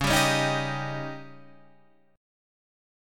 C#sus2b5 chord {x 4 5 6 4 3} chord